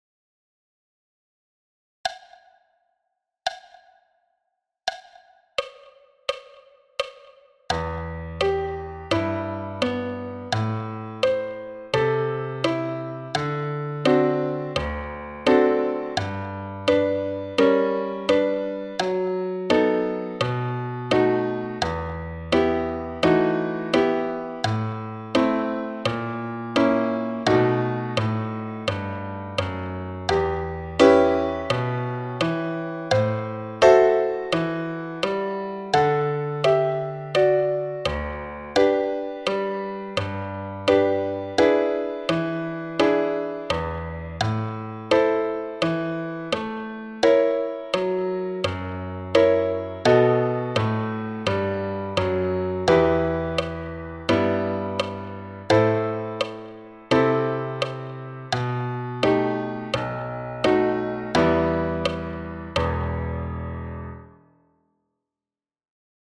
Etude 1- piano à 85 bpm
Etude-1-piano-a-85-bpm-1.mp3